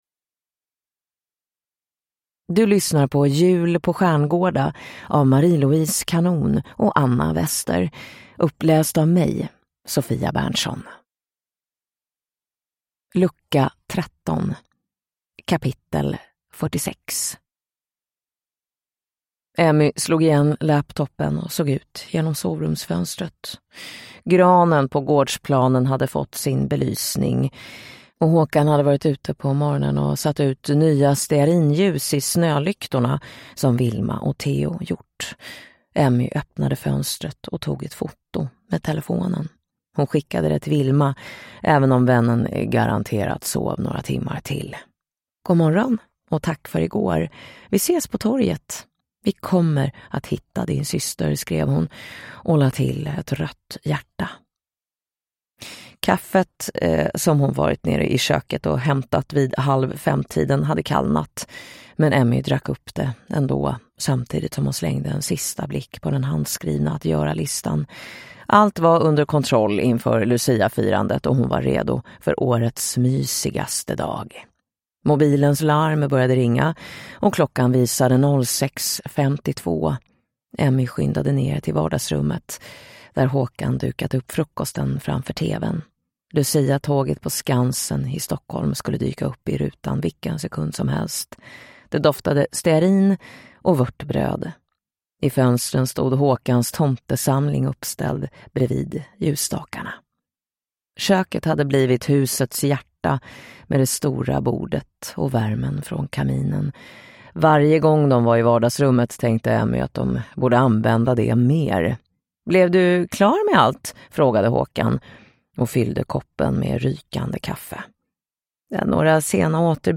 Jul på Stjärngårda: Lucka 13 – Ljudbok